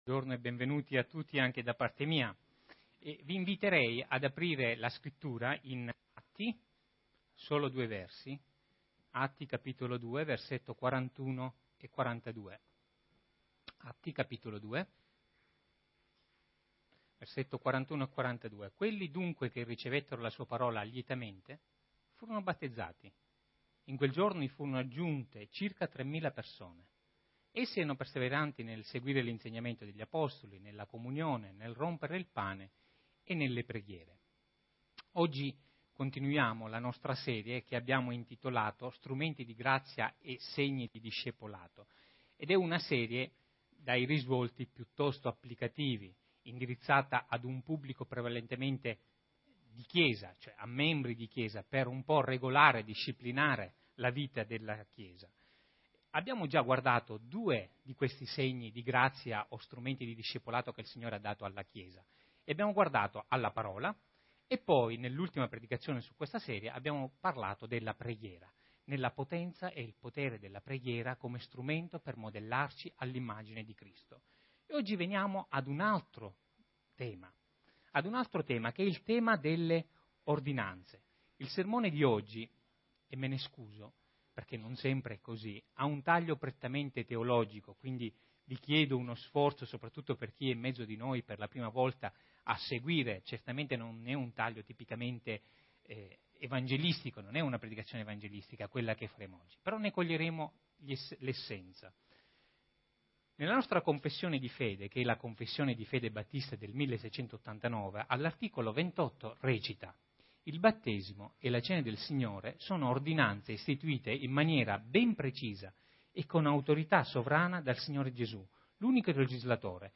Predicazioni